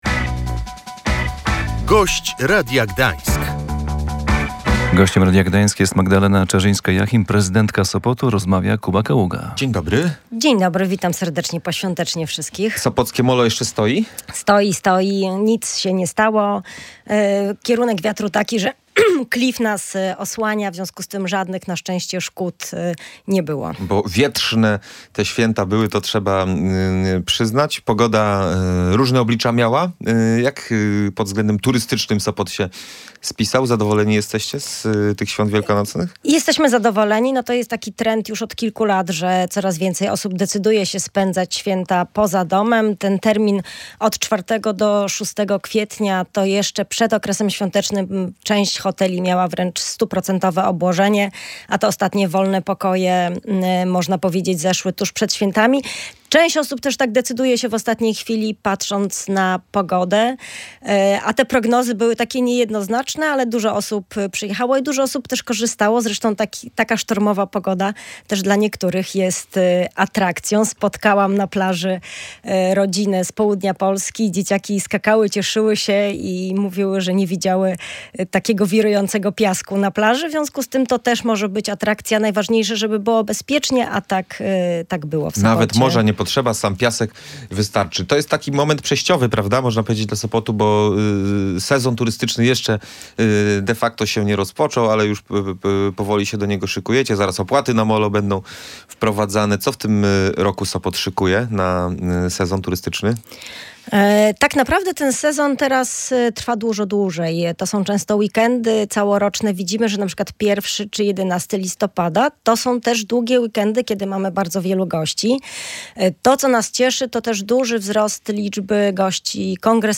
Zgodnie ze zmianami dotyczącymi najmu krótkoterminowego, które ma wprowadzić UE, każda kwatera będzie musiała być zarejestrowana w specjalnym systemie, a brak rejestracji skutkować ma karami i niemożnością umieszczenia oferty w serwisach rezerwacyjnych. Magdalena Czarzyńska-Jachim w porannej rozmowie na naszej antenie podkreślała, że jeśli najemcy będą łamać prawo, np. zakłócać porządek, lokal będzie mógł zostać usunięty z listy.